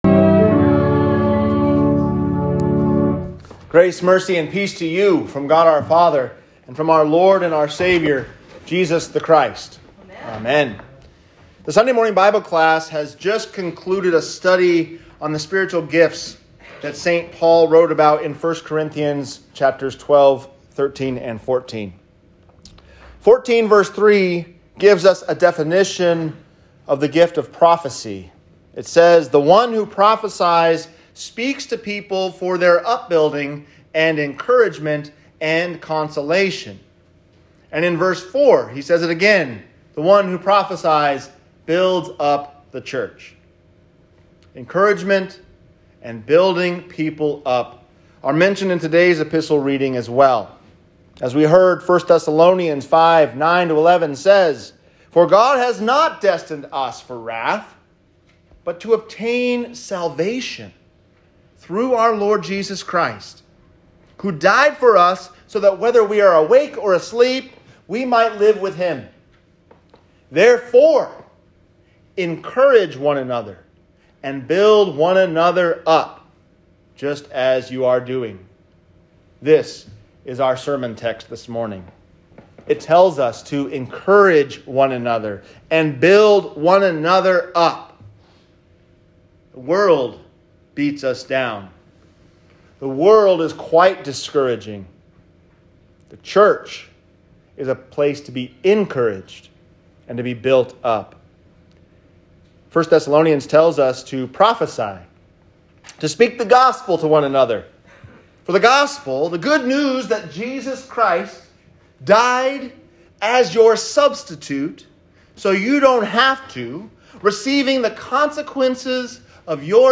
At Jesus’ Feet, Giving Thanks | Sermon